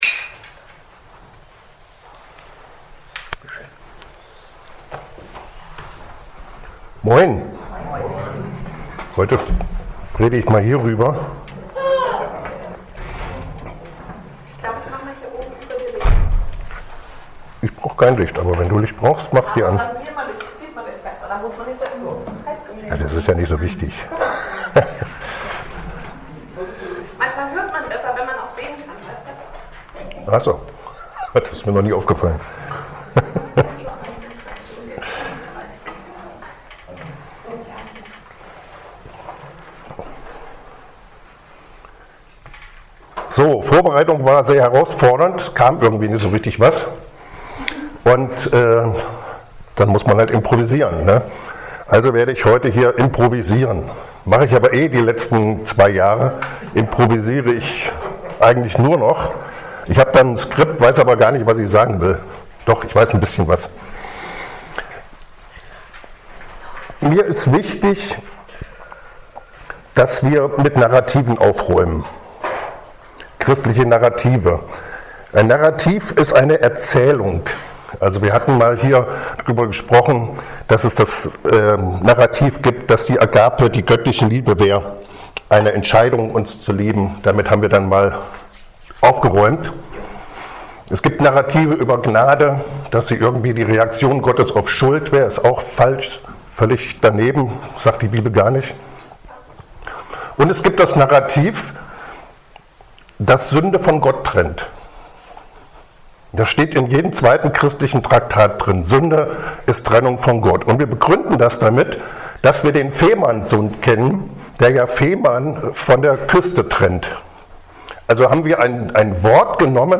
Externe Prediger